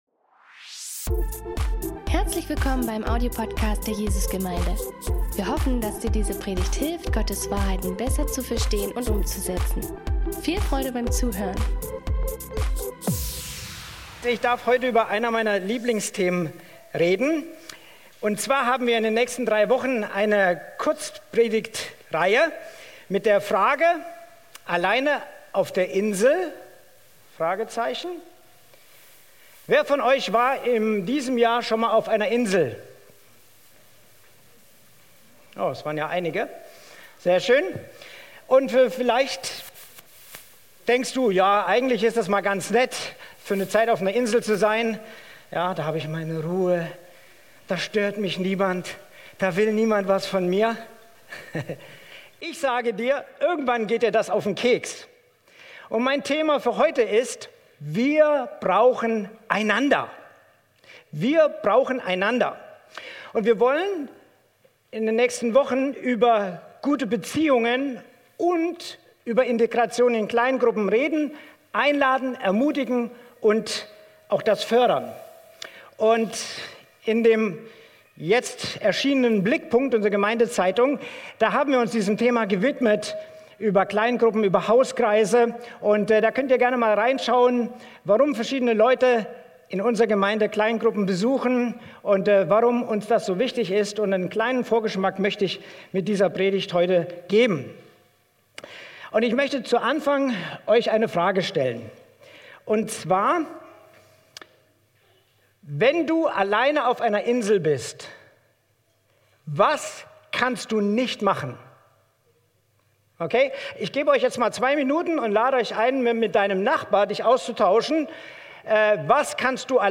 Sermons | Jesus Gemeinde Dresden